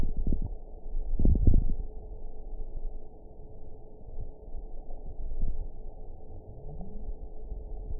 event 921682 date 12/16/24 time 21:45:41 GMT (4 months, 3 weeks ago) score 7.64 location TSS-AB03 detected by nrw target species NRW annotations +NRW Spectrogram: Frequency (kHz) vs. Time (s) audio not available .wav